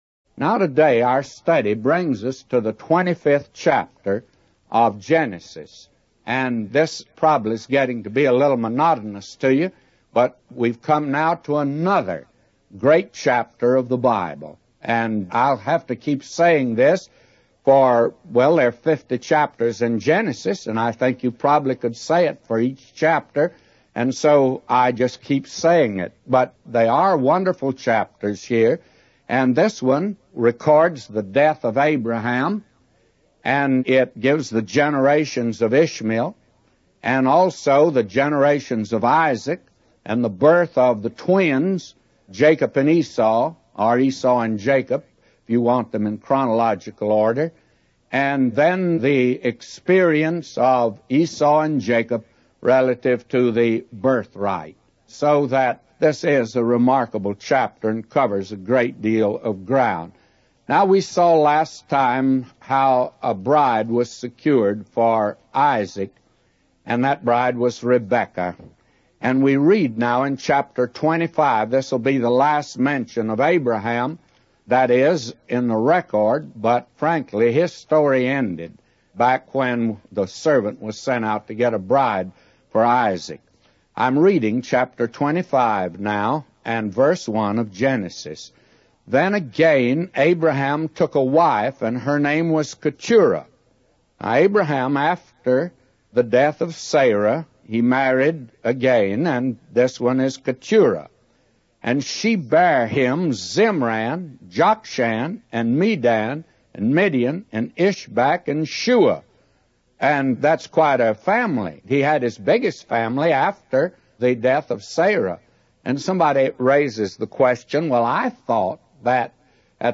A Commentary